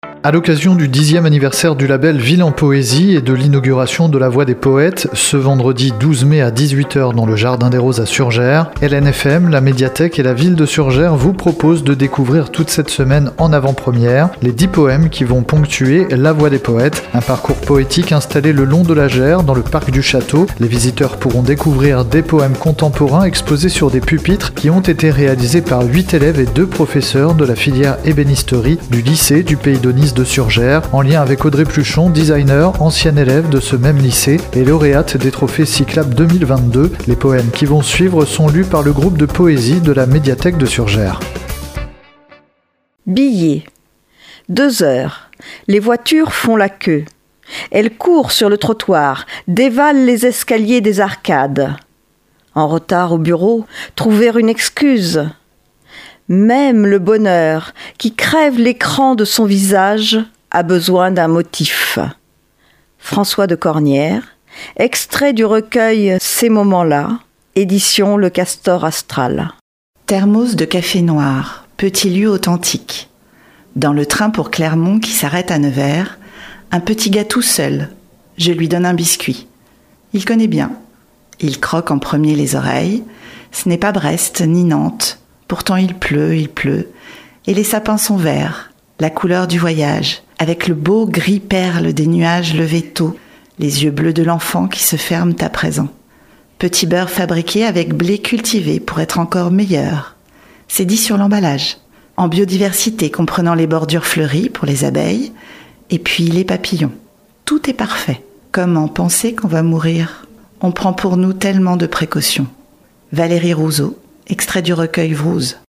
Les lectrices du groupe de poésie de la Médiathèque de Surgères sont venues au studio d’Hélène FM pour enregistrer les poèmes de la « Voie des poètes ».